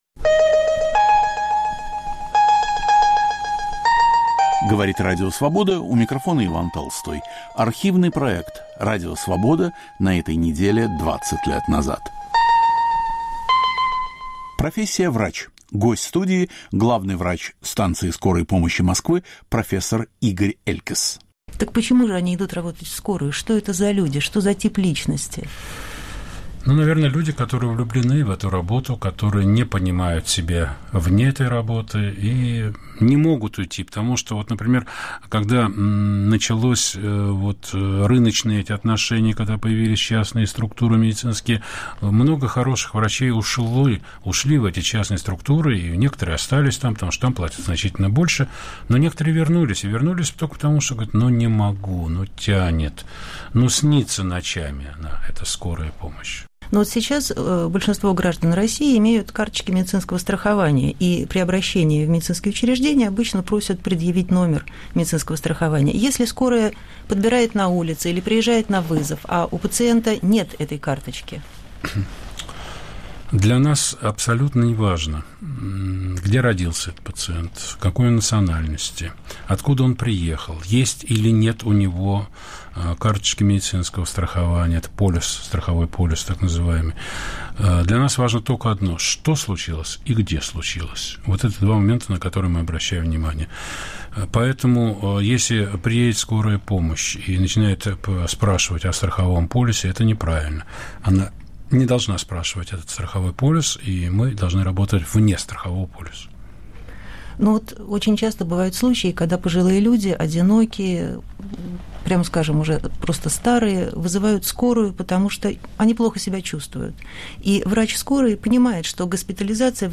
Профессия врач. Гость в студии